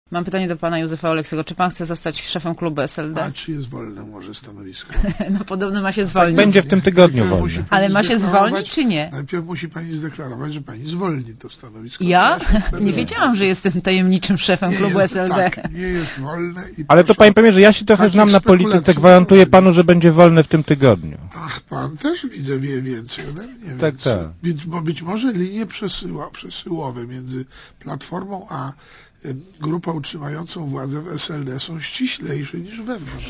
Posłuchaj Józefa Oleksego